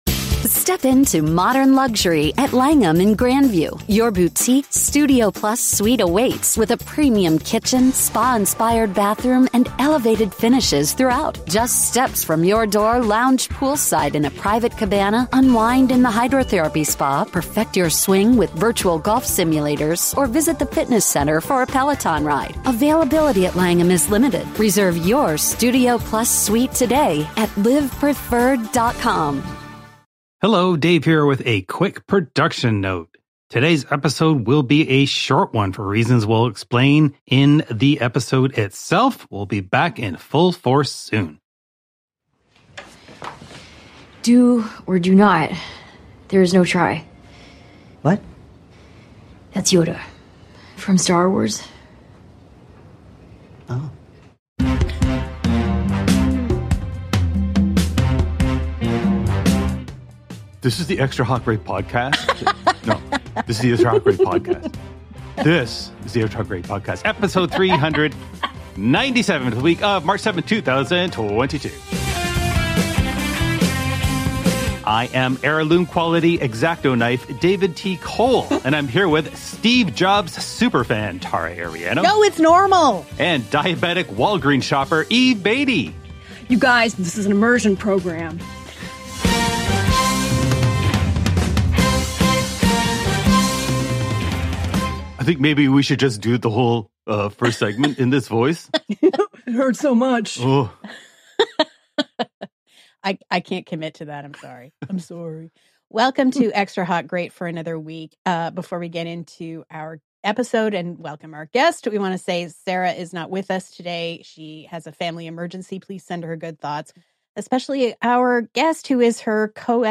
This drunk episode.